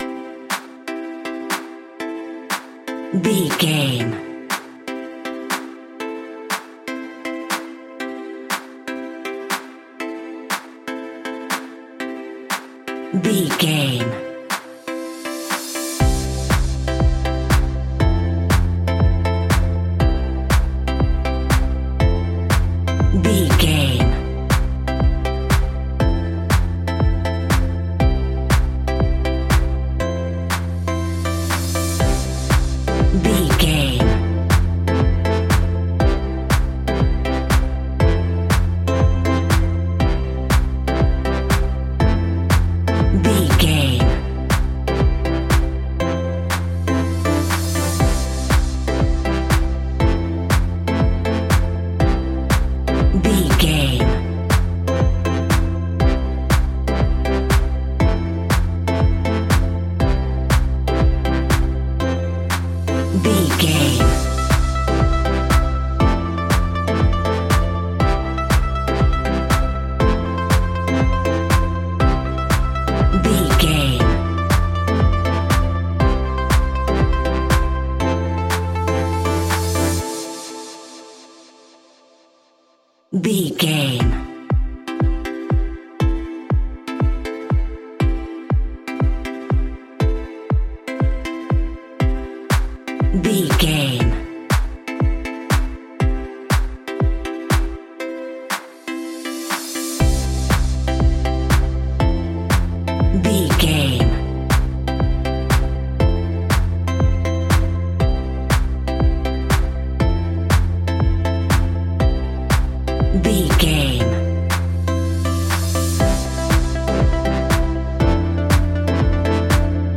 Ionian/Major
groovy
dreamy
smooth
drum machine
synthesiser
funky house
disco
upbeat
funky guitar
clavinet
synth bass